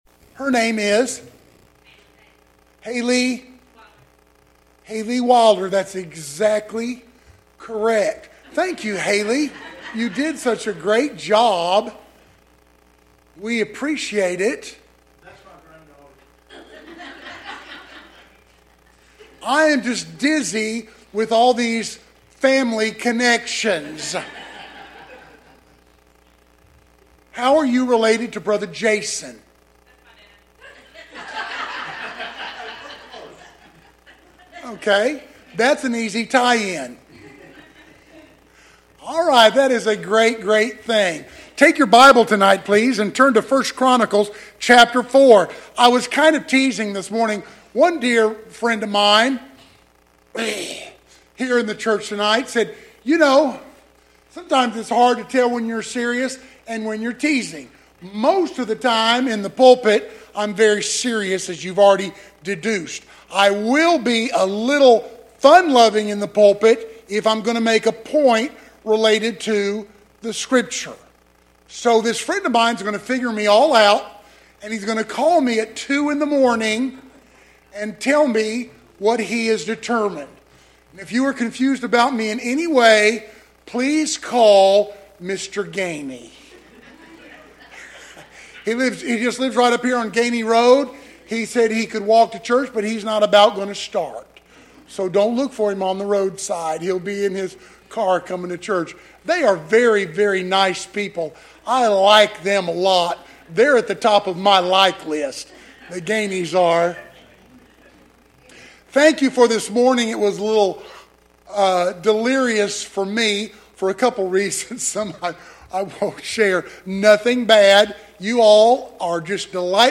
Check out the preaching, teaching from Pine Hill Baptist Church.